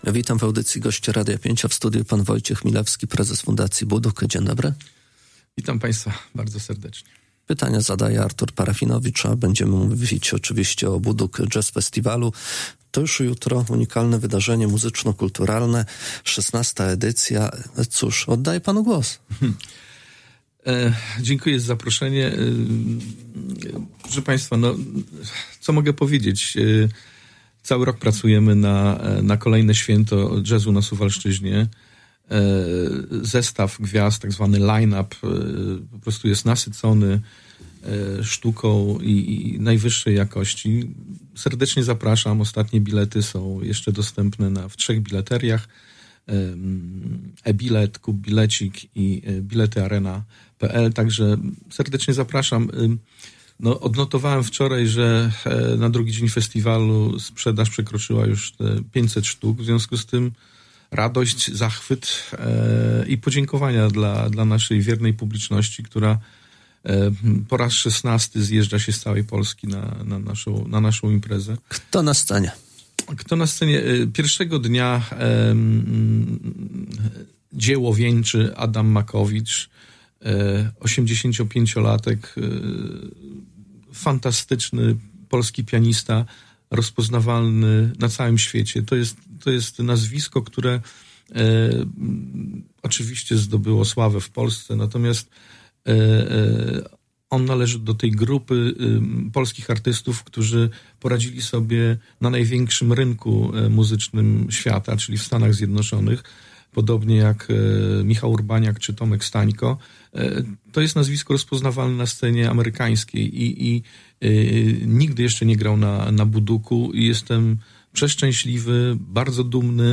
Cała rozmowa